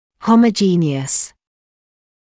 [ˌhɔmə’ʤiːnɪəs][ˌхомэ’джиːниэс]гомогенный, однородный; аллогенный (о трансплантате)
homogeneous.mp3